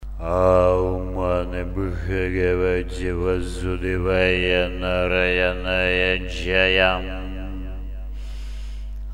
Читается протяжно, несколько замедленно, выразительно, на одном выдохе. Ударение падает на последнее слово «Джаям».